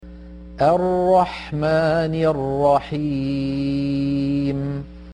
B. Prydnad och förbättring av egenskaper: Exempelvis att göra bokstaven (ر) lätt när den har en fathah eller dhammah, som i: